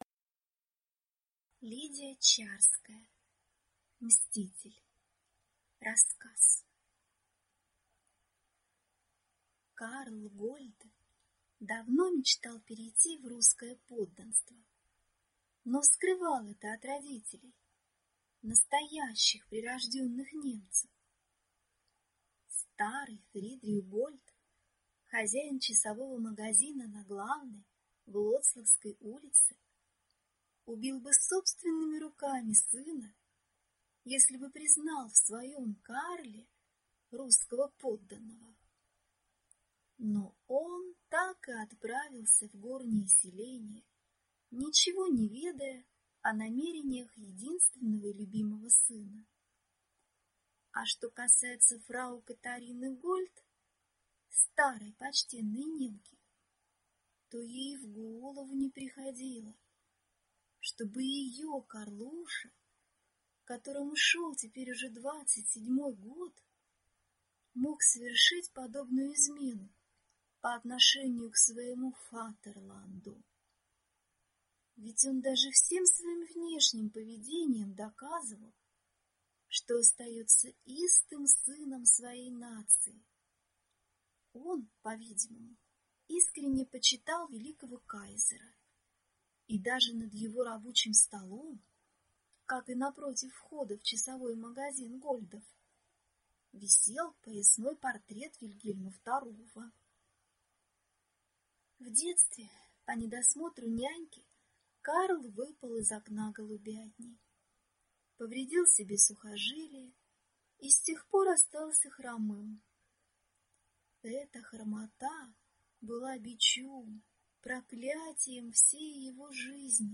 Аудиокнига Мститель | Библиотека аудиокниг